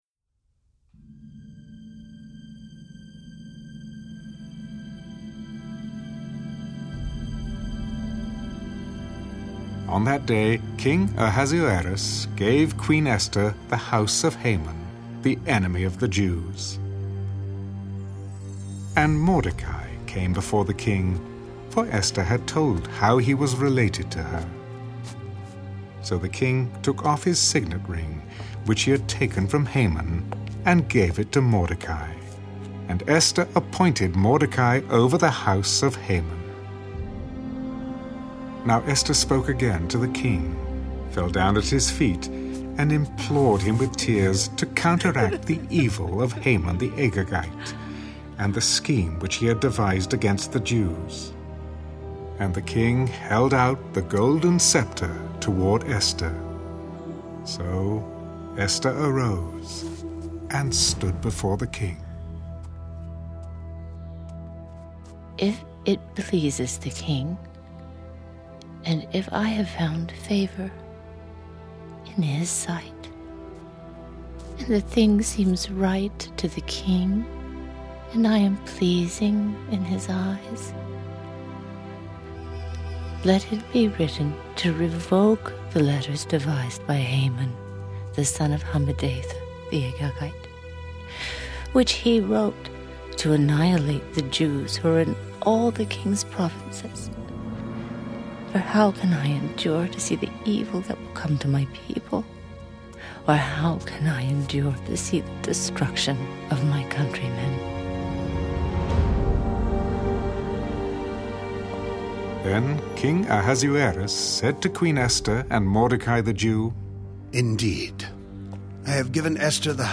(Audio Bible)